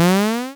LevelUp.wav